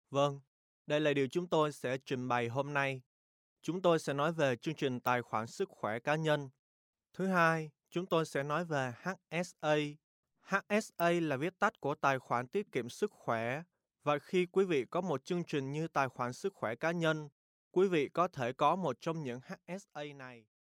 Professionelle Sprecher und Sprecherinnen
Männlich